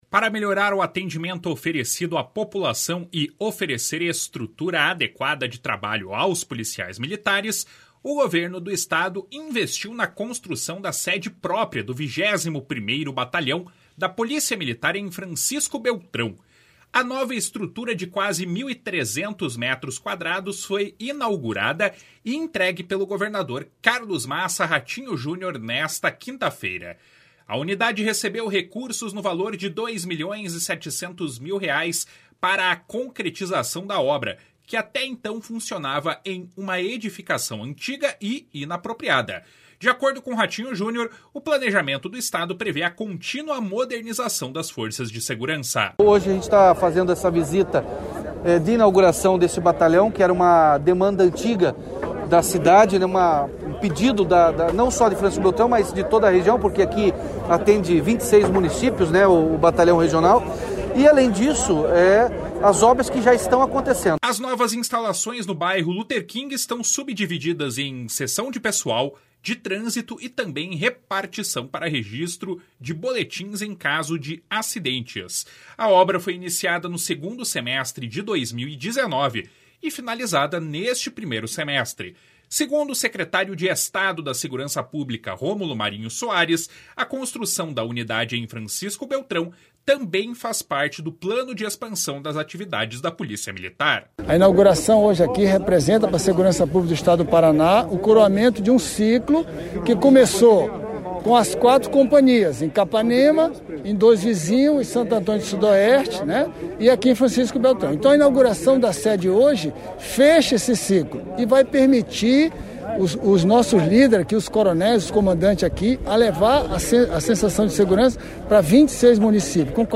// SONORA ROMULO MARINHO SOARES //
// SONORA CLEBER FONTANA //